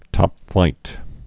(tŏpflīt)